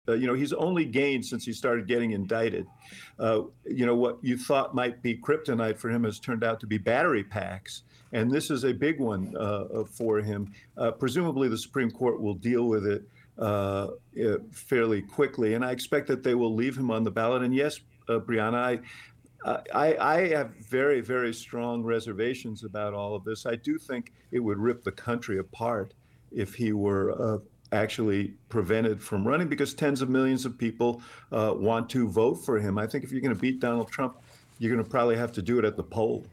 Former advisor to President Barack Obama David Axelrod expressed his concerns about this strategy in an appearance with Brianna Keller on CNN this past Sunday.